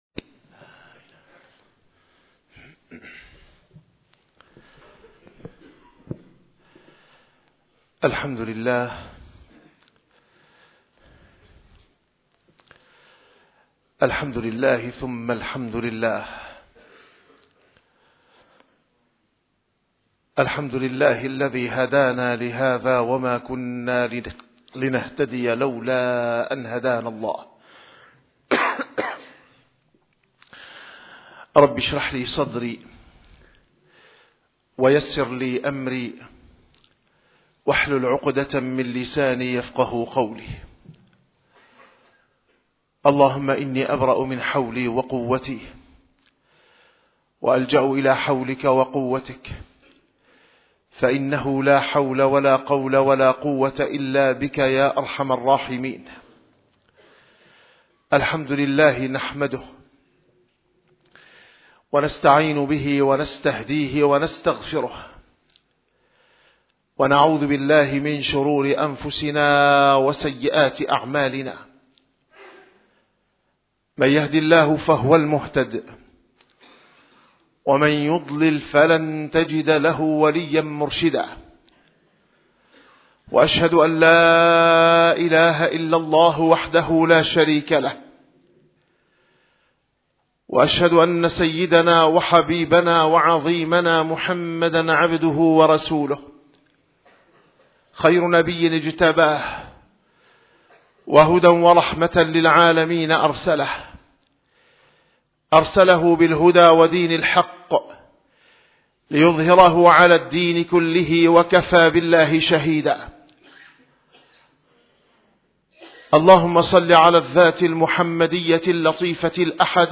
- الخطب - الهجرة النبويّة (همّة سيدنا عمر بعد الهجرة ثمرة إيمانية)